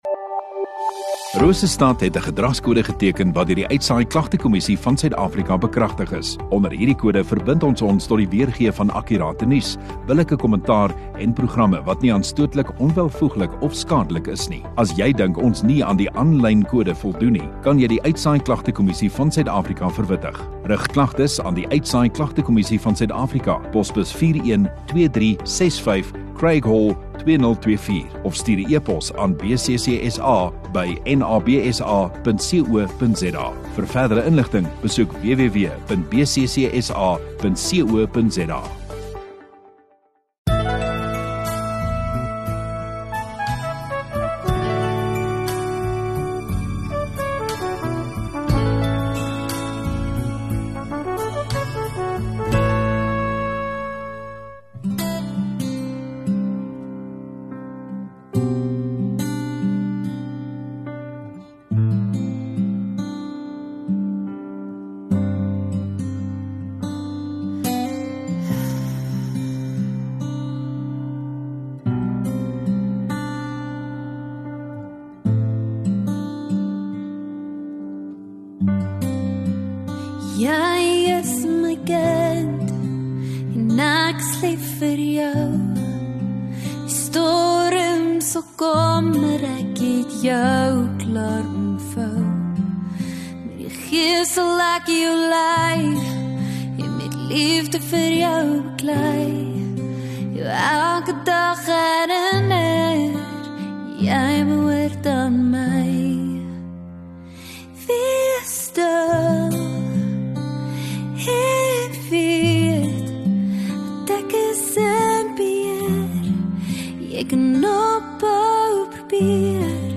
19 Apr Saterdag Oggenddiens